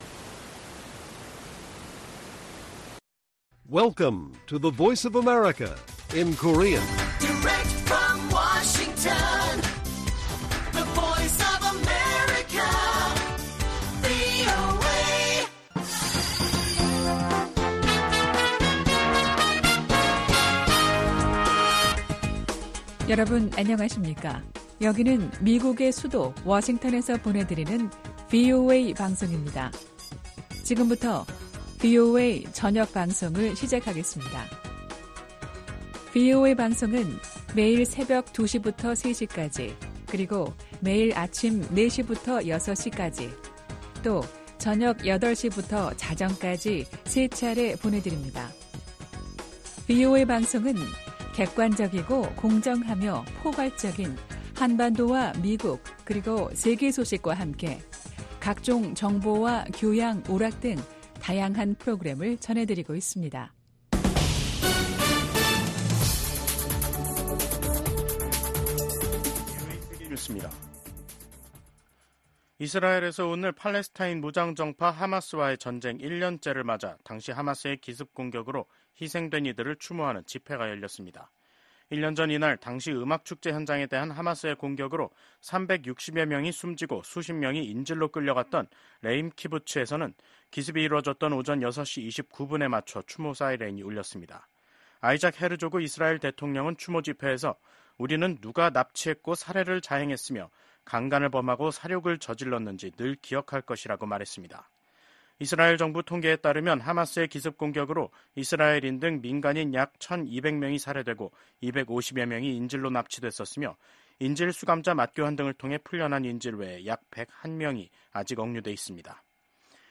VOA 한국어 간판 뉴스 프로그램 '뉴스 투데이', 2024년 10월 7일 1부 방송입니다.